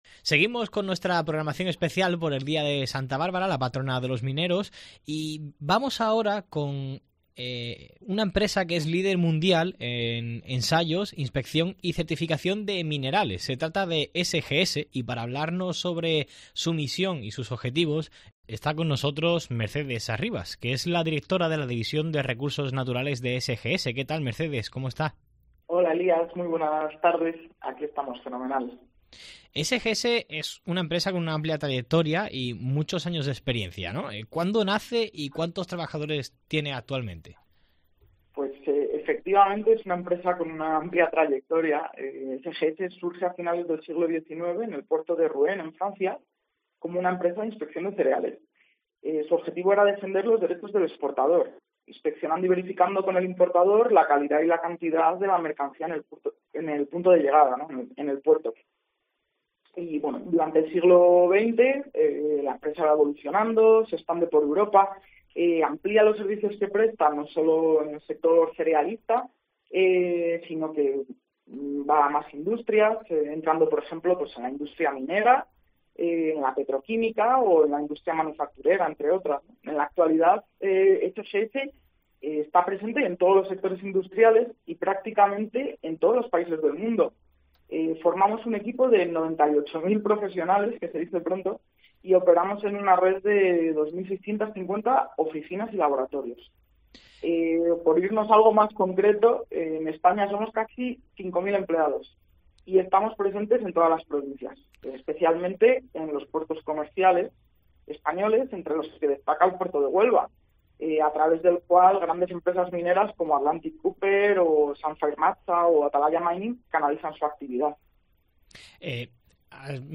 DÍA DE SANTA BÁRBARA Entrevista